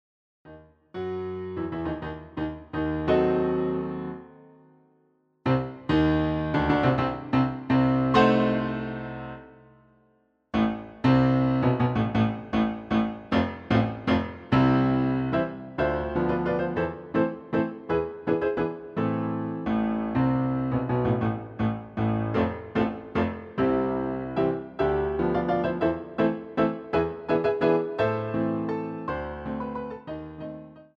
piano roll